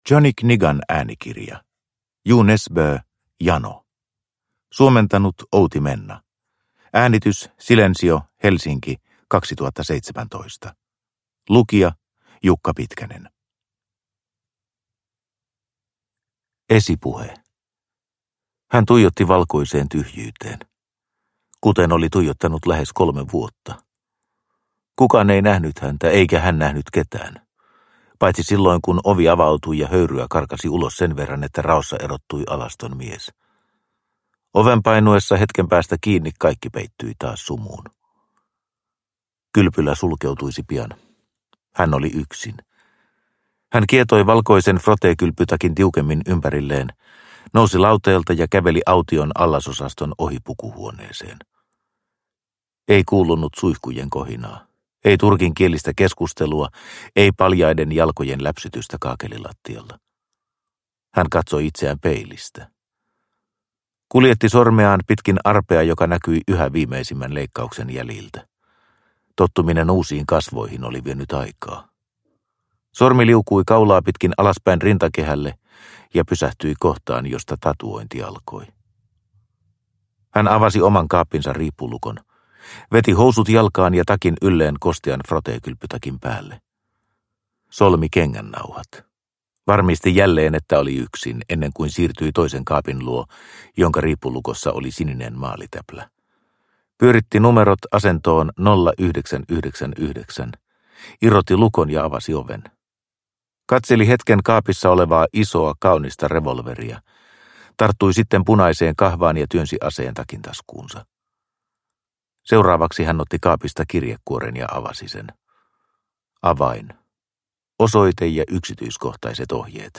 Jano – Ljudbok – Laddas ner